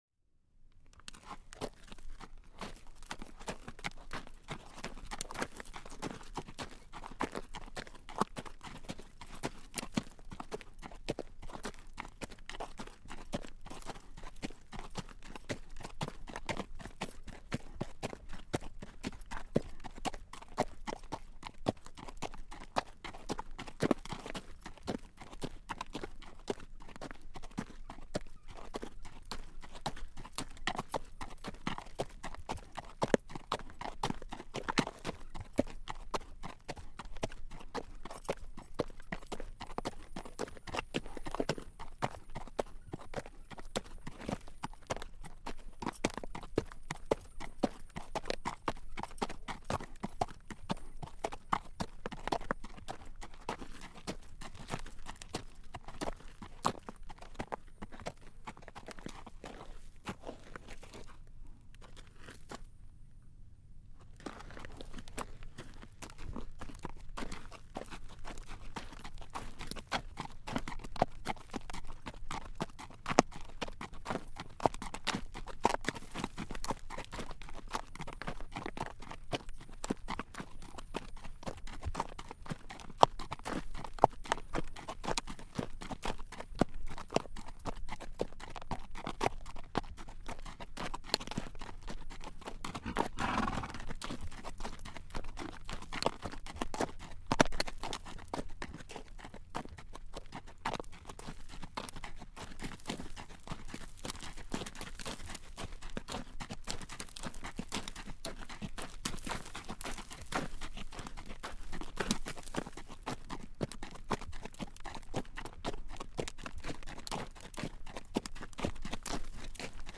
دانلود صدای اسب 75 از ساعد نیوز با لینک مستقیم و کیفیت بالا
جلوه های صوتی